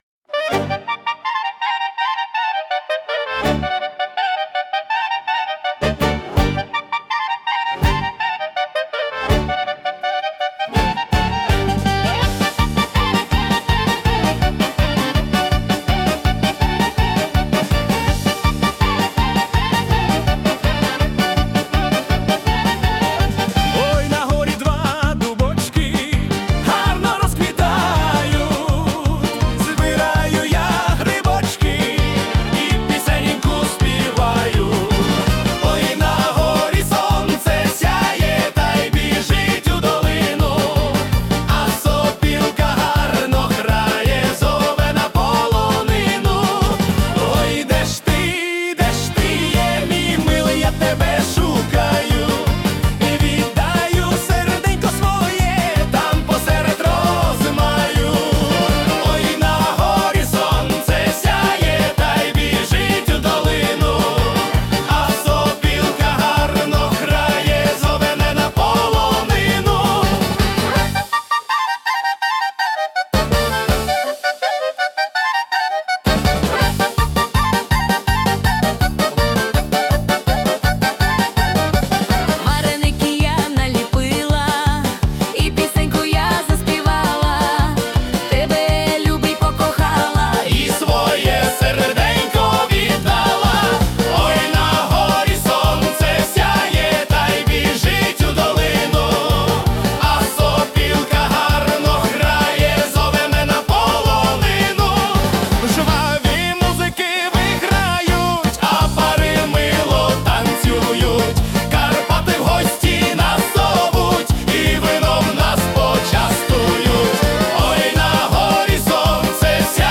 Ой на горі сонце сяє (полька)